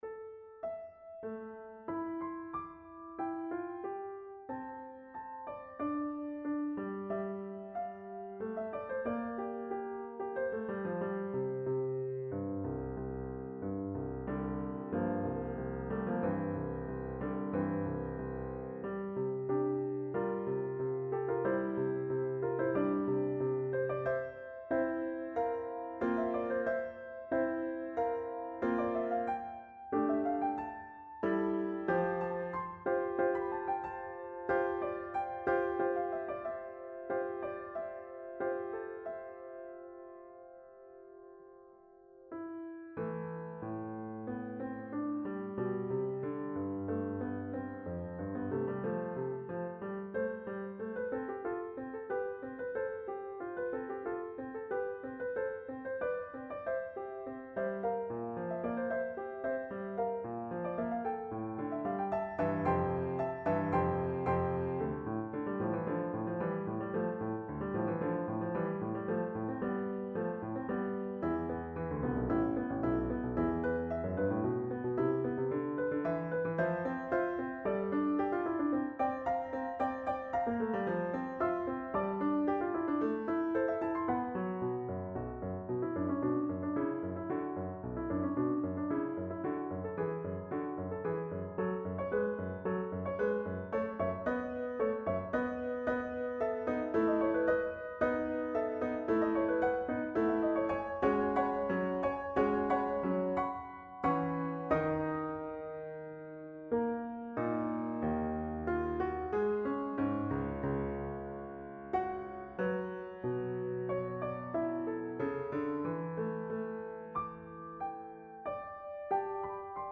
MIDI recording